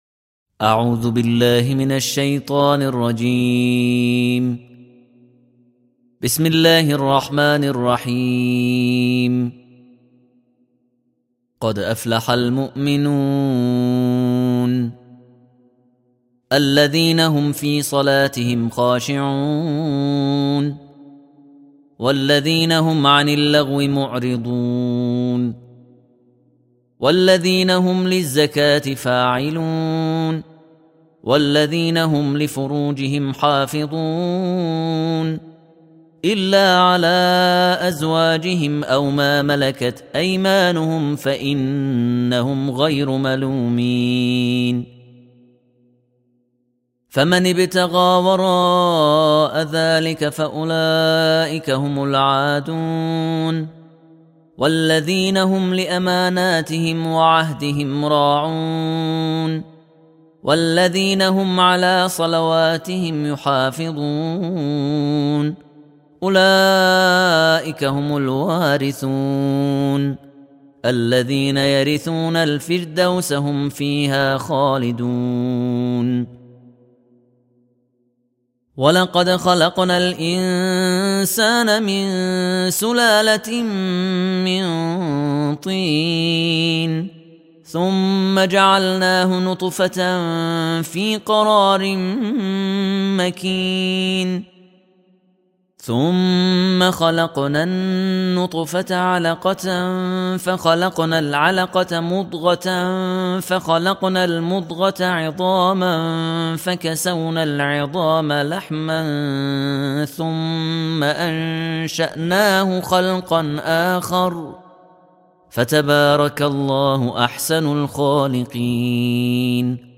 تلاوت یک جزء از قرآن به پیروی از ائمه هدی و بزرگان دین می‌تواند ما را در این مسیر یاریگر باشد.